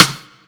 Clap
Original creative-commons licensed sounds for DJ's and music producers, recorded with high quality studio microphones.
clap-sound-f-sharp-key-02-WC8.wav